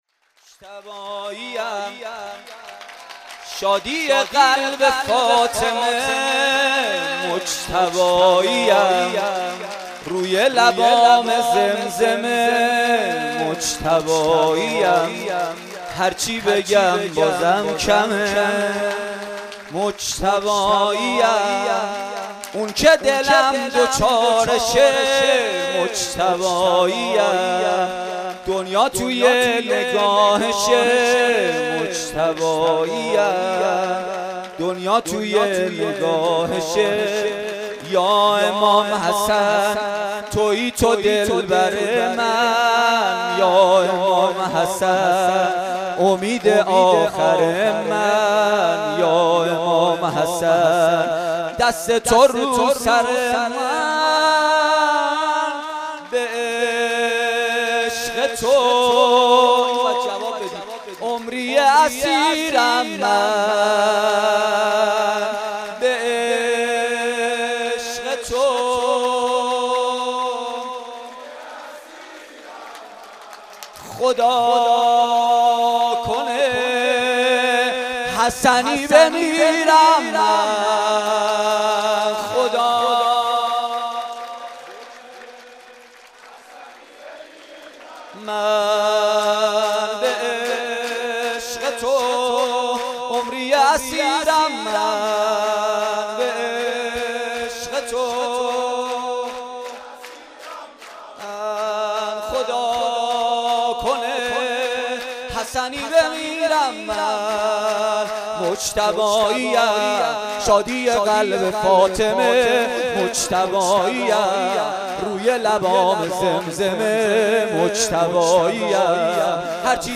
شب پانزدهم ماه رمضان با مداحی کربلایی محمدحسین پویانفر در ولنجک – بلوار دانشجو – کهف الشهداء برگزار گردید.
شب پانزدهم ماه رمضان/کربلایی محمدحسین پویانفر شب پانزدهم ماه رمضان با مداحی کربلایی محمدحسین پویانفر در ولنجک – بلوار دانشجو – کهف الشهداء برگزار گردید.
مدح امام حسن علیه السلام
روضه امام حسن علیه السلام